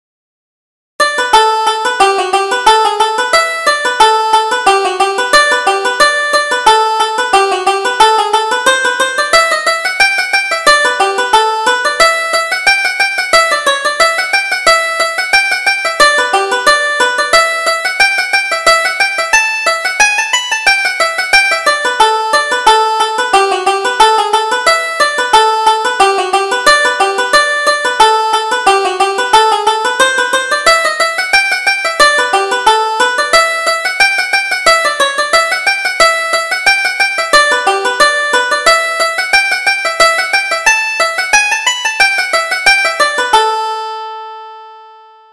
Reel: Beamish's Goat